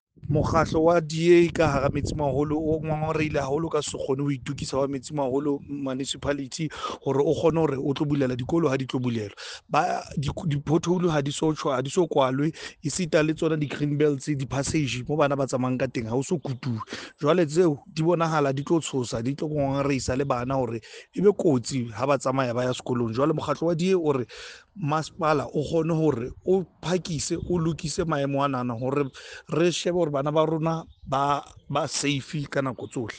Sesotho soundbites by Cllr Thabang Rankoe